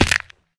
fallbig2.ogg